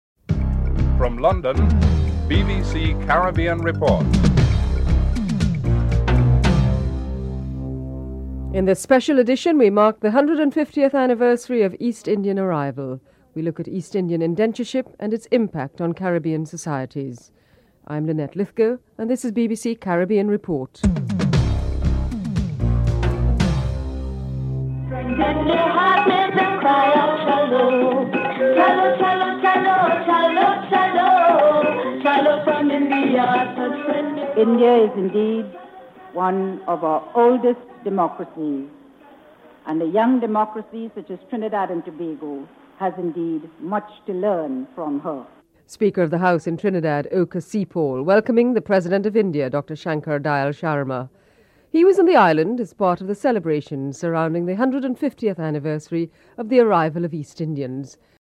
West Indies cricket remains a shining example of unity in the region and Sonny Ramadhin talks about his experiences.
3. Interview with some Afro-Guyanese persons about the racial tensions in the society (02:29-04:49)